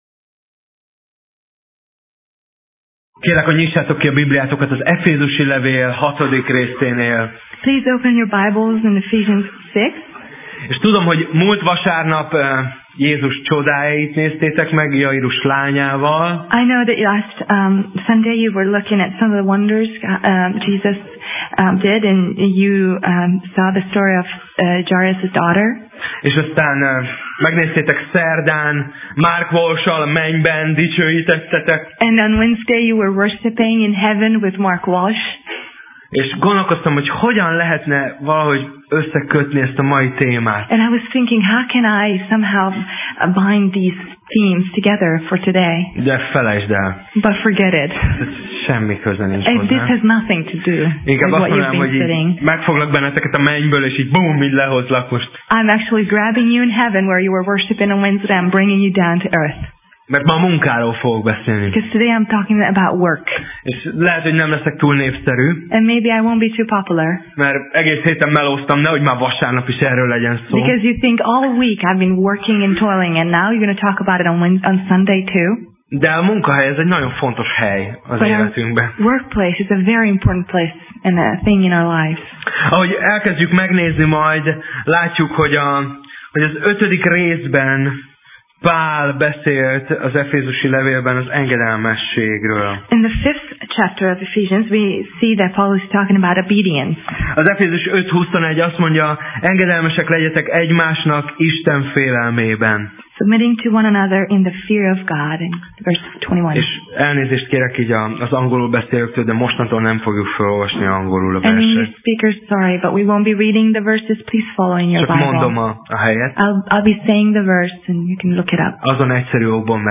Sorozat: Tematikus tanítás Passage: Efezus (Ephesians) 6:5–9 Alkalom: Vasárnap Reggel